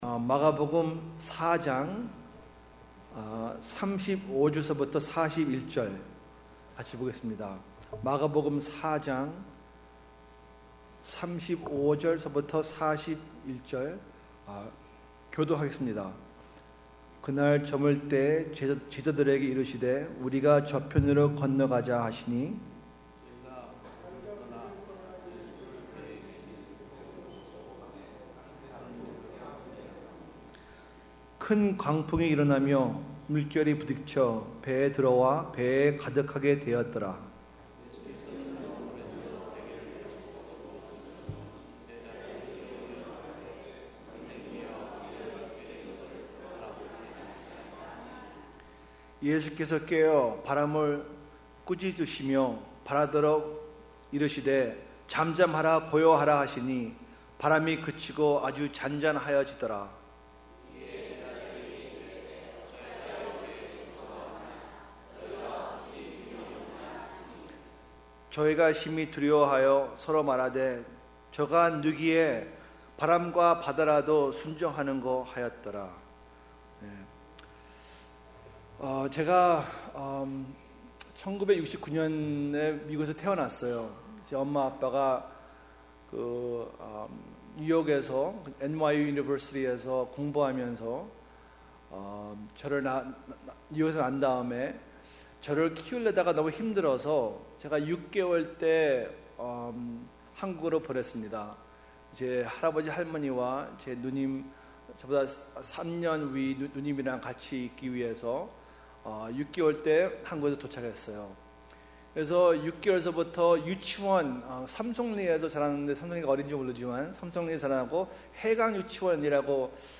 특별집회 - 마가복음 4장 35-41절